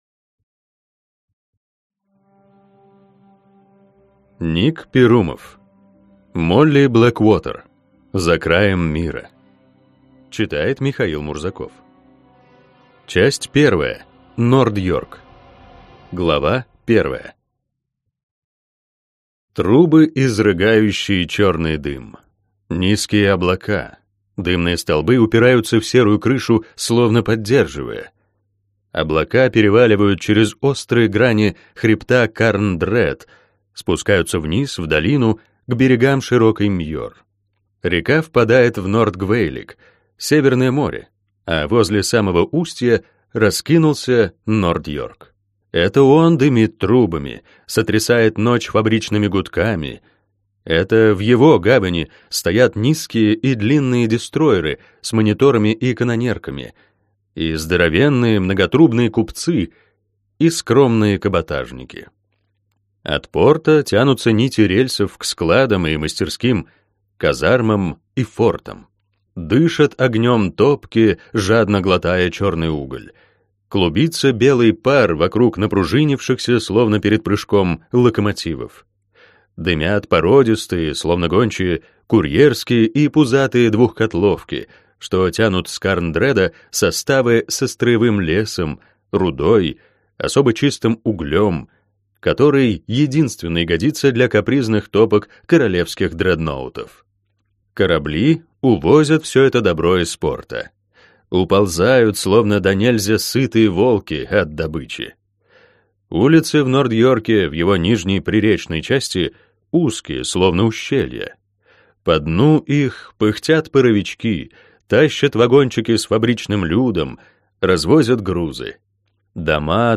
Аудиокнига Молли Блэкуотер. За краем мира | Библиотека аудиокниг